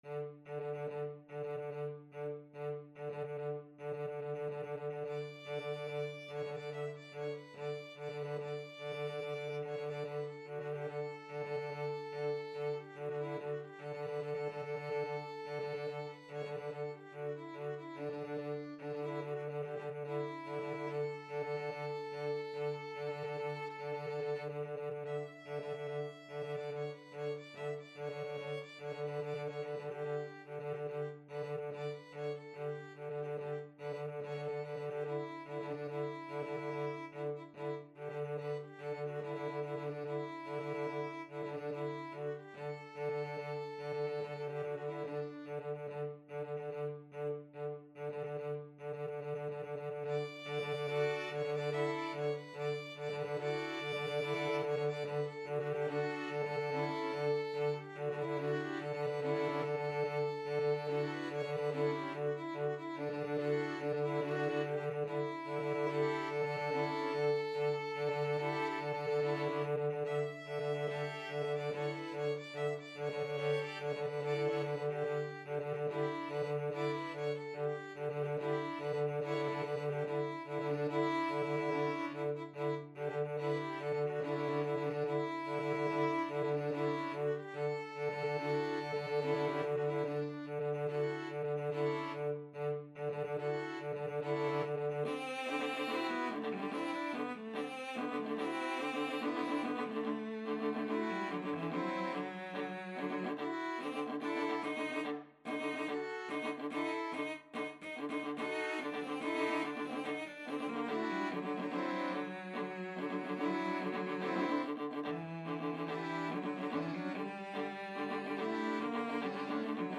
String Quartet version
Violin 1Violin 2ViolaCello
3/4 (View more 3/4 Music)
Classical (View more Classical String Quartet Music)